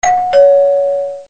короткие
звонкие
Забавный звук на смс